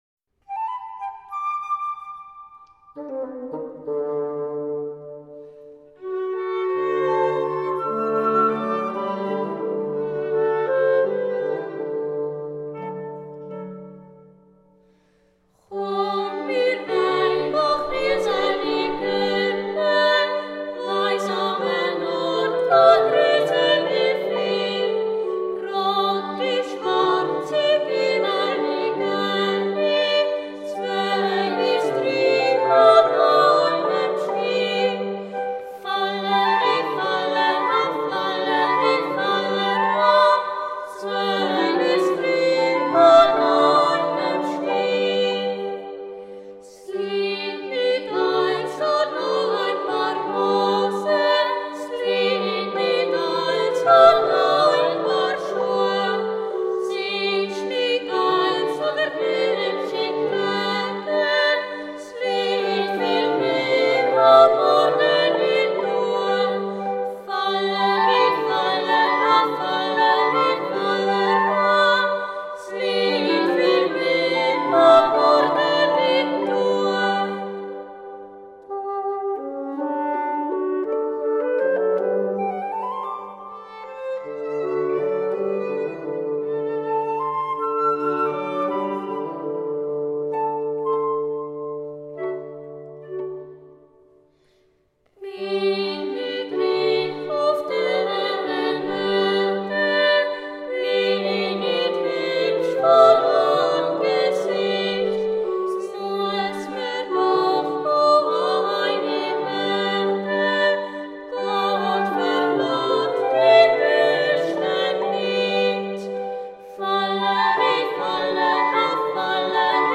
Zurich Boys’ Choir – The most beautiful Swiss folk songs and tunes (Vol. 2)
Trad., arr., André Scheurer. Insrumental accompaniment